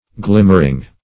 glimmering - definition of glimmering - synonyms, pronunciation, spelling from Free Dictionary